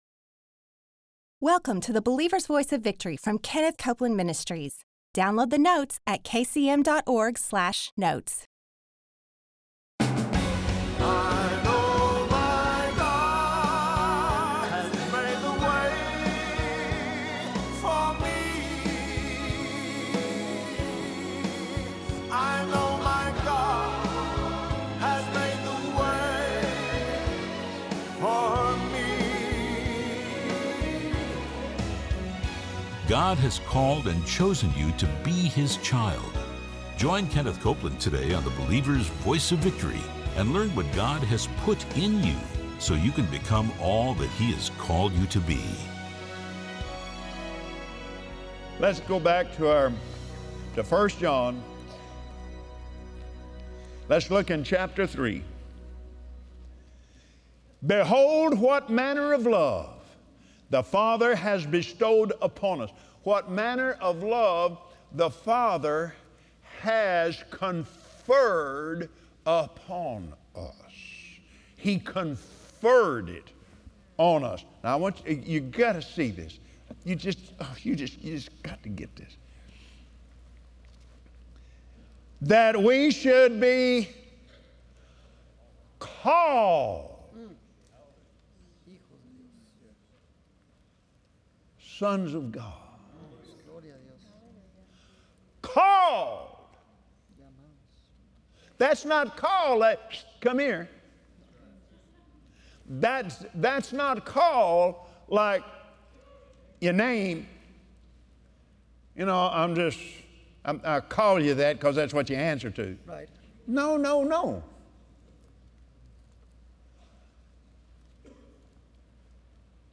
He has chosen and called you His child. Watch Kenneth Copeland on Believer’s Voice of Victory share how living from the truth of His love for you empowers you to be blessed, prosperous and victorious.